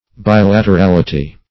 Bilaterality \Bi*lat`er*al"i*ty\, n.